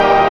3505R CHORD.wav